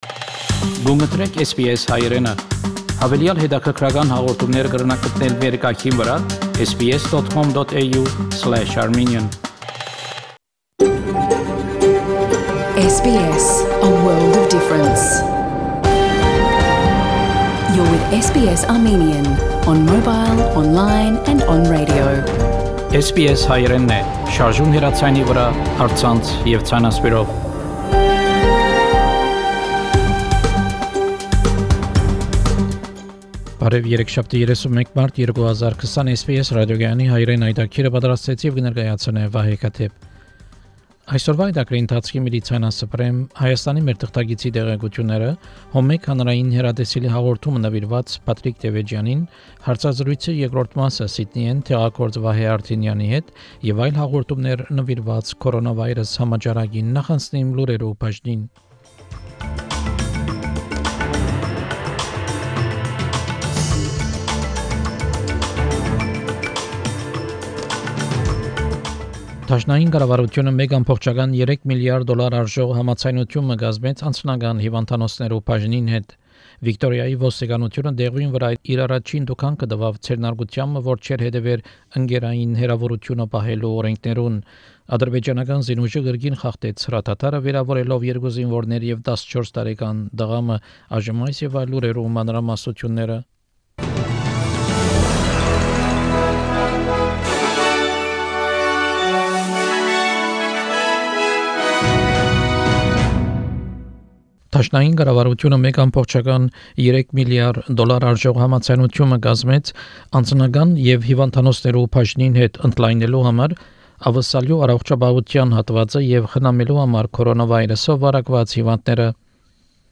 Armenian news bulletin - 31 March 2020